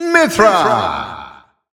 The announcer saying Mythra's name in Dutch releases of Super Smash Bros. Ultimate.
Mythra_Dutch_Announcer_SSBU.wav